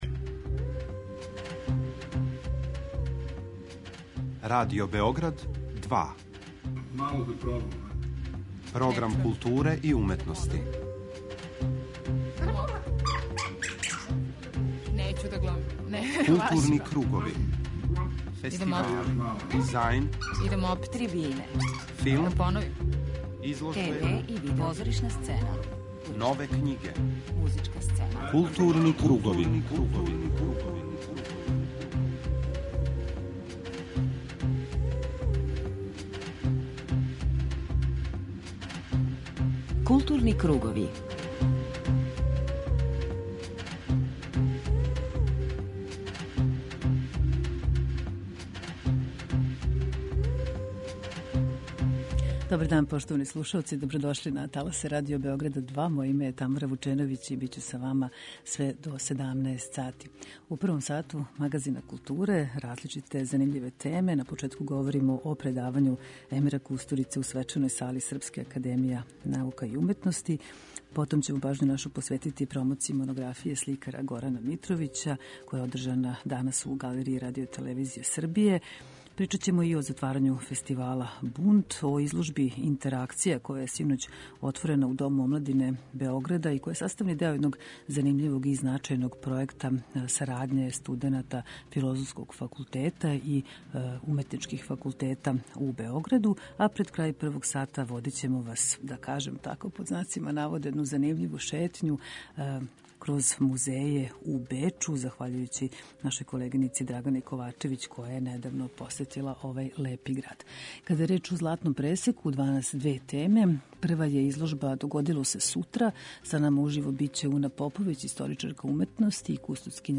преузми : 41.02 MB Културни кругови Autor: Група аутора Централна културно-уметничка емисија Радио Београда 2.